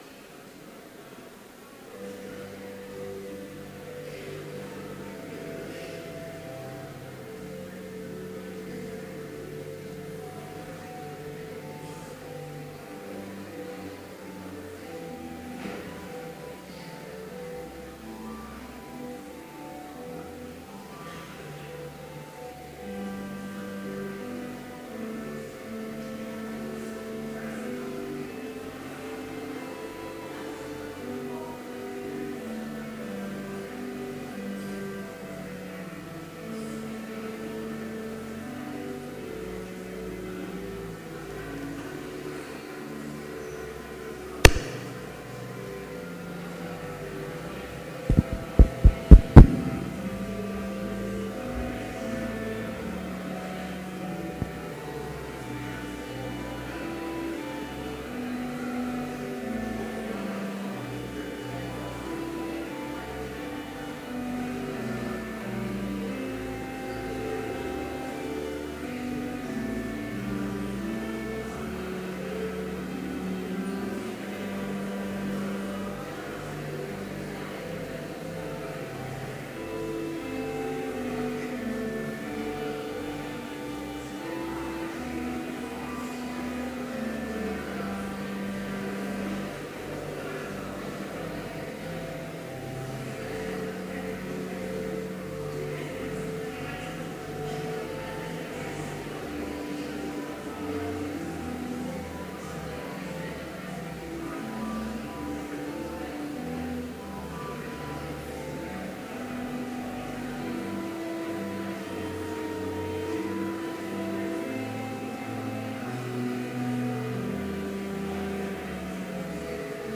Complete service audio for Chapel - September 28, 2015
Choir: Nunc Dimittis (Song of Simeon) J. Stainer